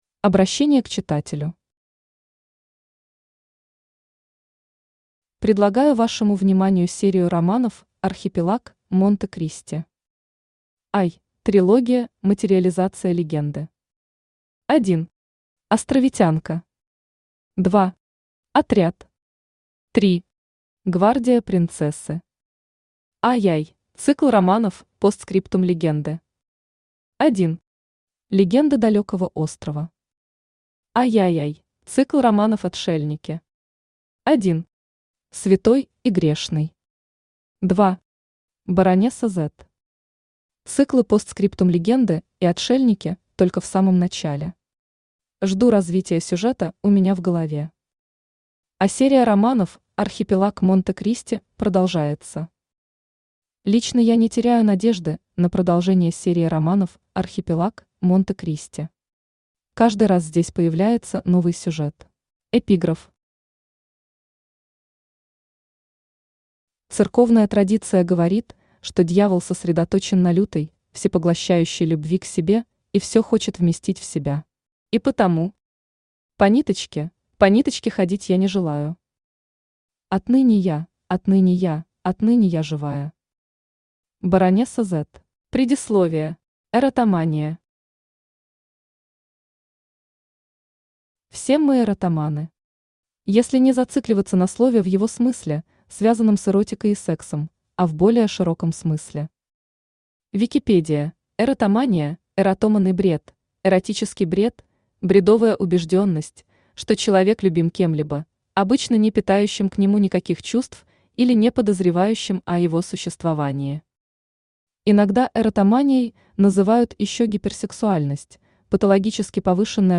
Аудиокнига Баронесса Z. Цикл «Отшельники». Том 2 | Библиотека аудиокниг
Том 2 Автор Геннадий Анатольевич Бурлаков Читает аудиокнигу Авточтец ЛитРес.